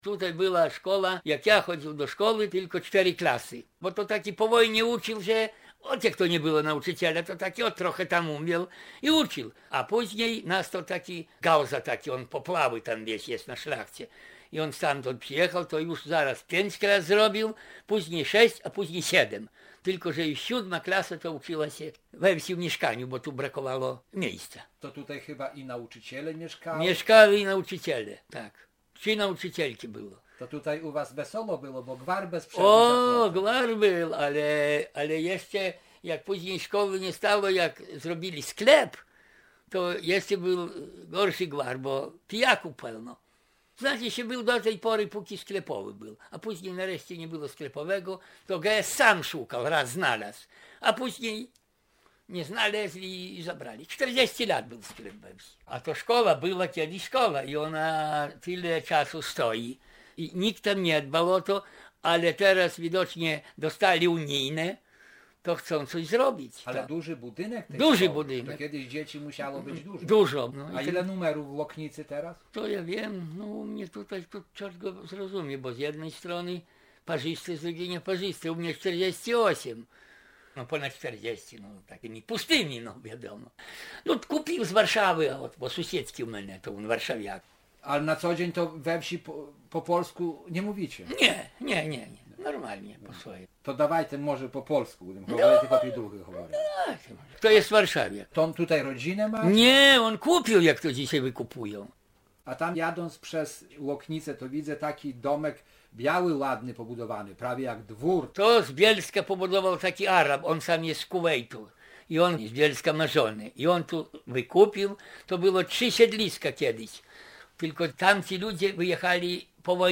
Gra dla siebie. Cicho, z uczuciem, z zamyśleniem.